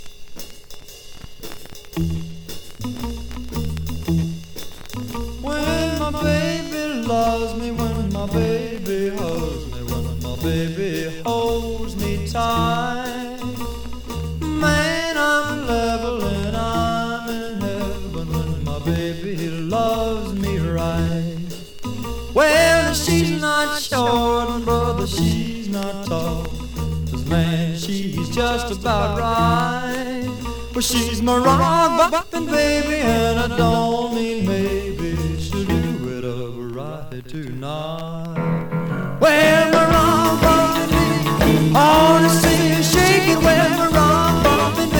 Rockabilly, Rock & Roll　Germany　12inchレコード　33rpm　Mono